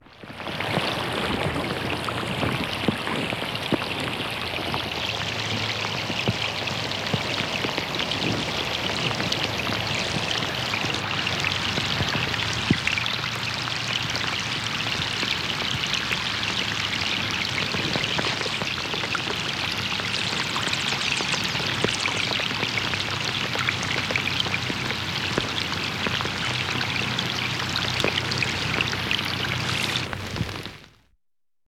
Rain.mp3